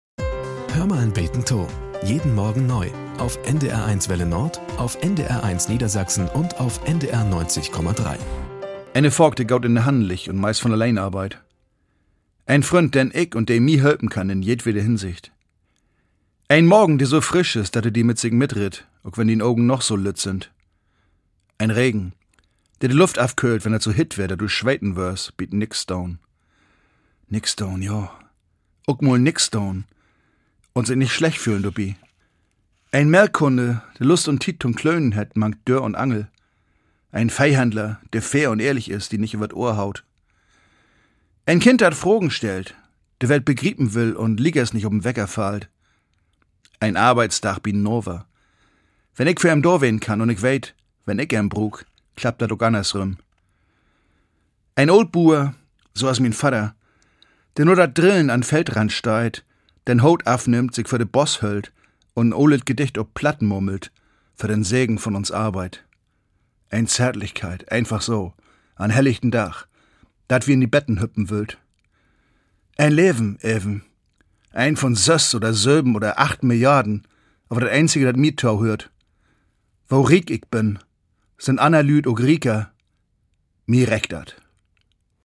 Nachrichten - 21.06.2023